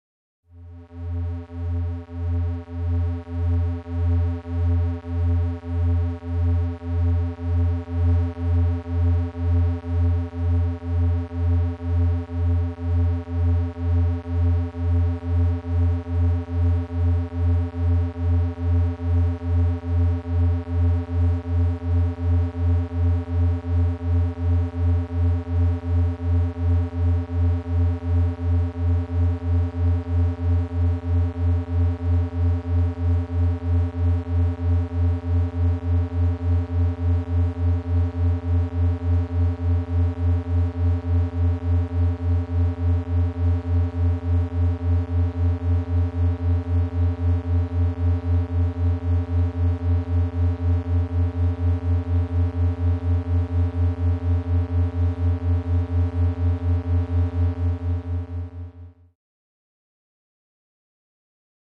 Электронные звуки скачать, слушать онлайн ✔в хорошем качестве